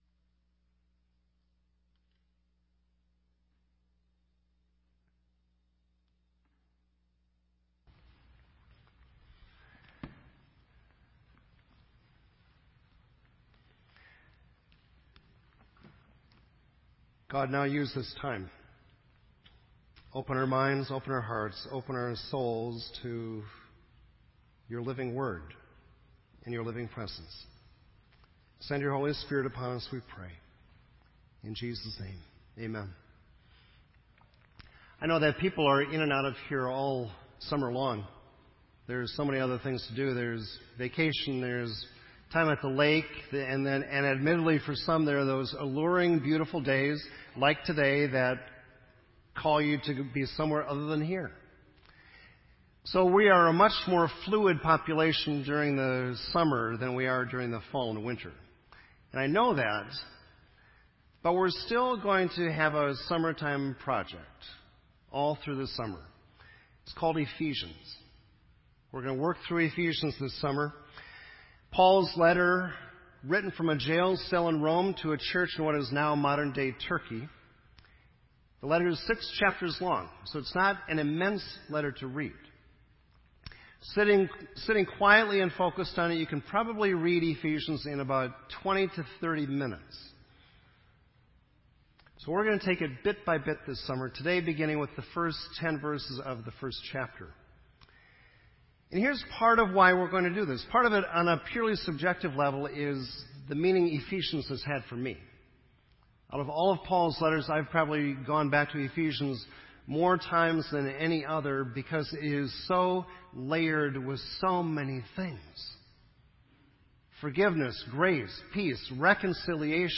This entry was posted in Sermon Audio on June 6